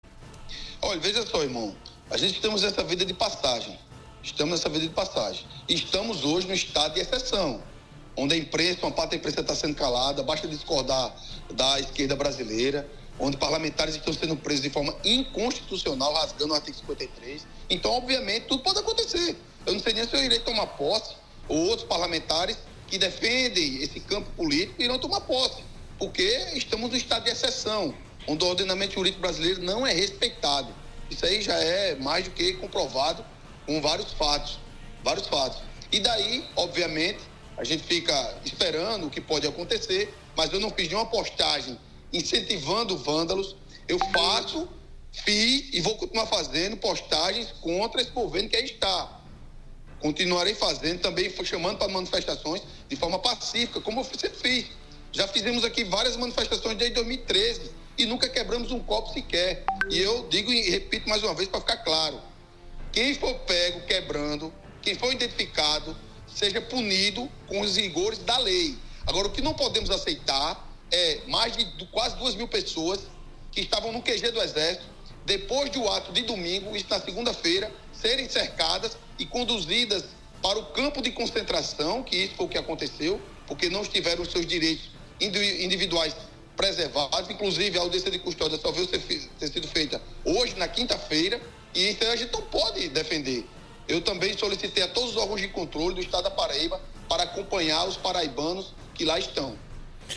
A declaração é do deputado federal eleito Cabo Gilberto (PL) ao comentar, durante entrevista ao programa 60 Minutos, do Sistema Arapuan de Comunicação na noite desta quinta-feira (12), as decisões do ministro do Supremo Tribunal Federal (STF), Alexandre de Moraes.